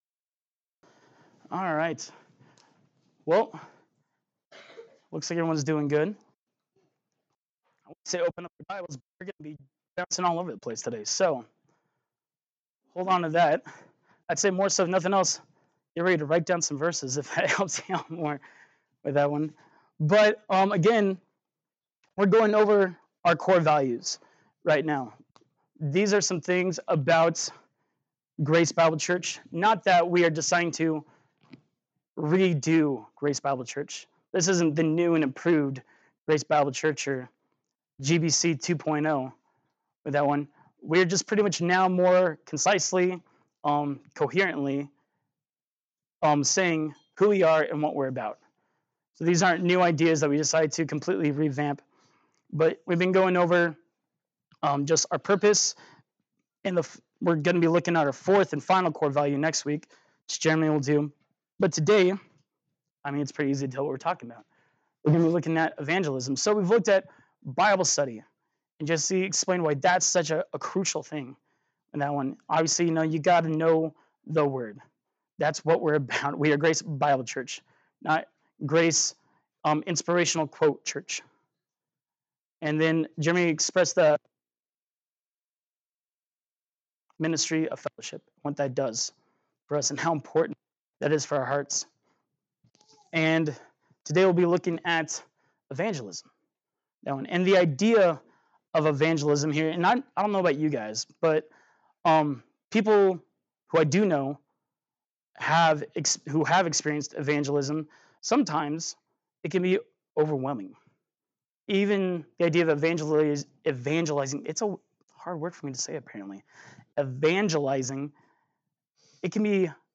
Passage: Acts 1:8 Service Type: Sunday Morning Worship